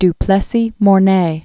(d-plĕ-sē môr-nā, dü-)